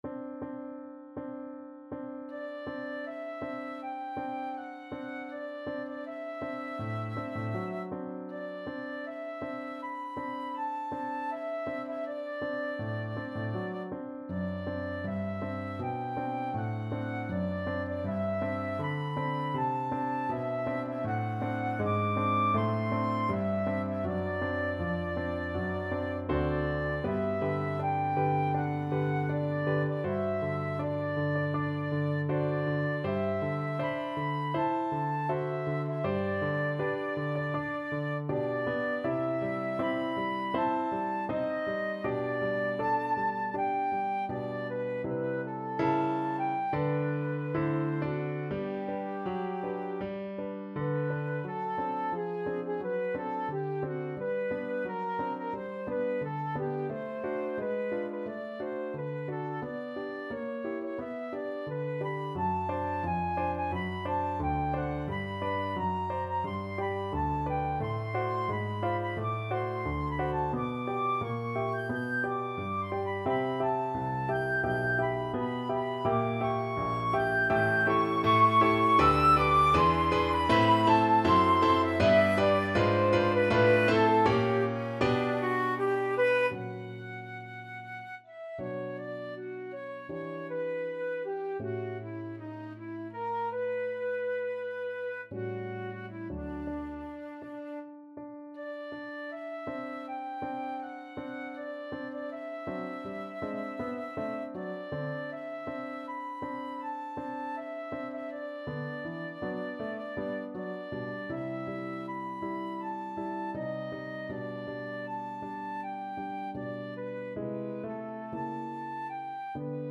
Flute
G major (Sounding Pitch) (View more G major Music for Flute )
4/4 (View more 4/4 Music)
Andante espressivo
Classical (View more Classical Flute Music)